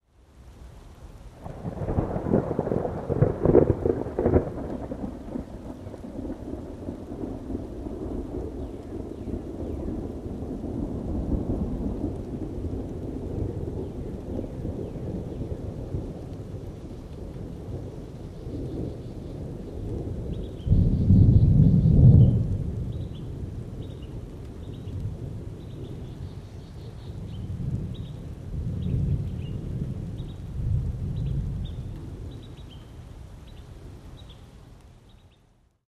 am_rain_thunder_05_hpx
Thunder rumbles over light rain as birds chirp in background. Rain, Thunderstorm Storm Weather, Thunderstorm